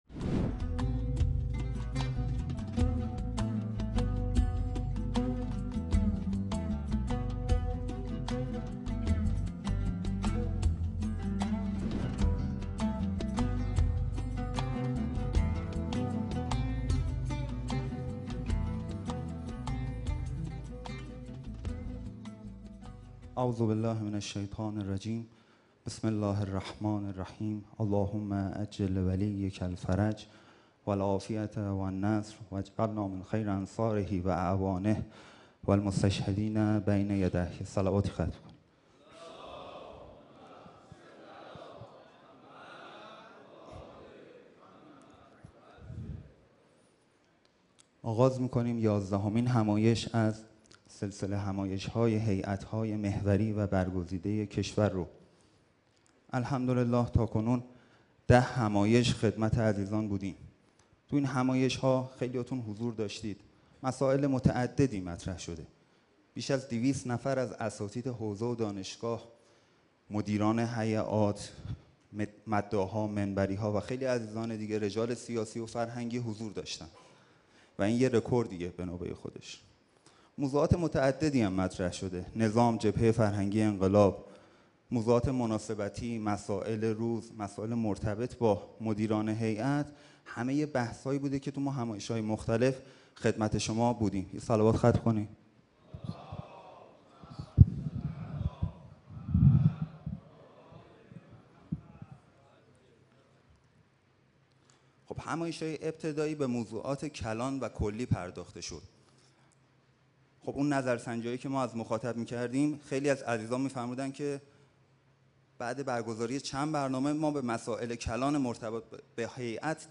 یازدهمین همایش هیأت‌های محوری و برگزیده کشور با محوریت هیأت و خانواده
شهر مقدس قم_جمکران